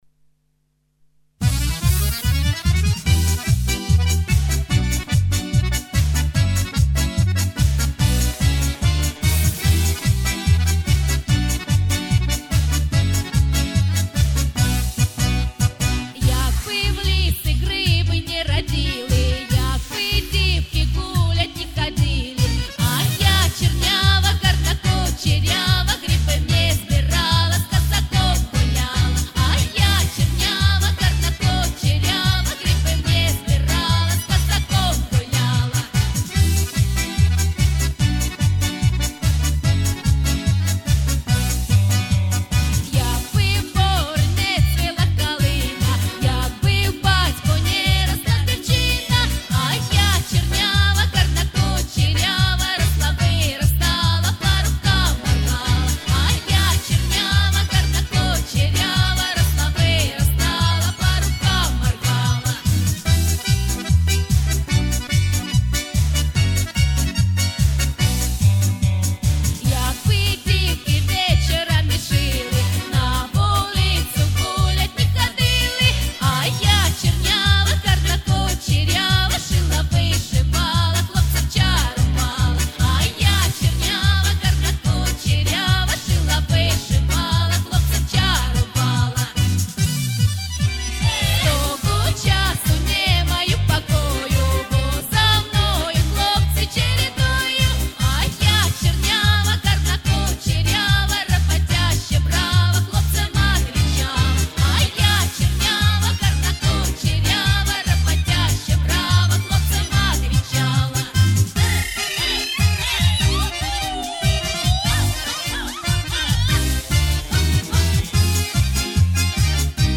Украинская народная песня
Девушка спела с настроением, но главные громкие опорные ноты очень неточно, с расчетом что за счет лихости настроения должно прокатить. Такое часто бывает в любительском народном пении.